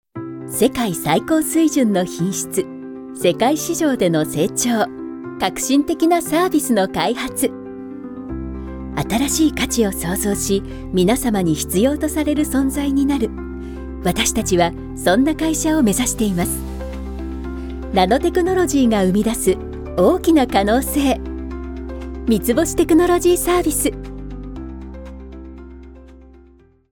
Vídeos Corporativos
Sua voz suave, clara e identificável é altamente versátil, tornando-a a escolha certa para várias grandes corporações e órgãos governamentais.
RodeNT1-A, Focusrite Scarlett 4i4, Audacity, Motor de som
Mezzo-sopranoSoprano